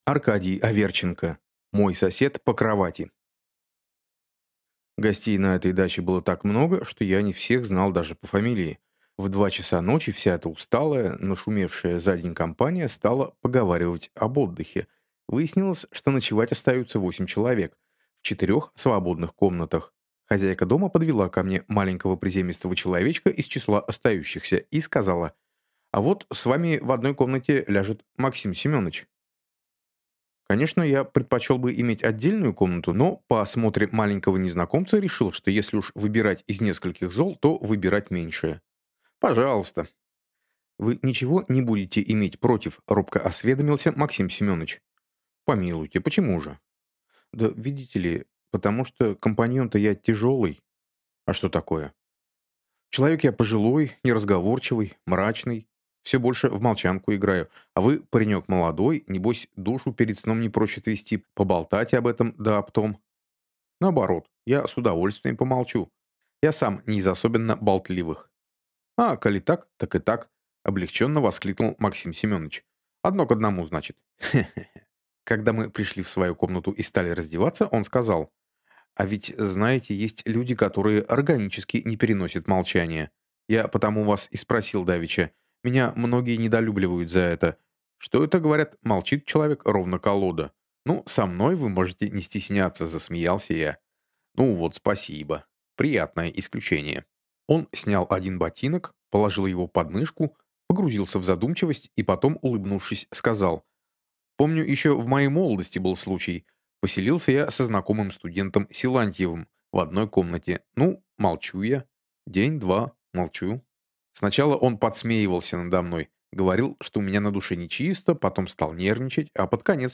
Аудиокнига Мой сосед по кровати | Библиотека аудиокниг